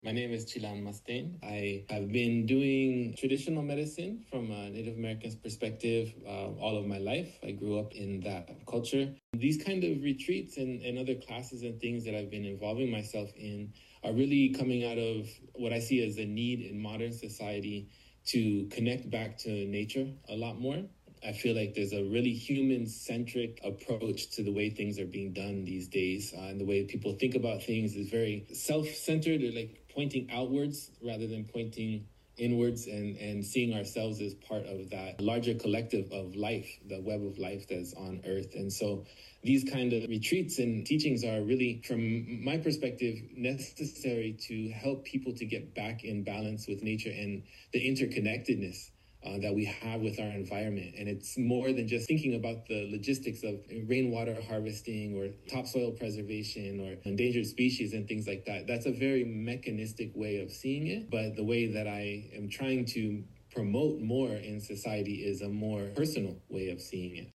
This snippet was taken from the live Q&A for the Spiritual Connection to Nature Retreat.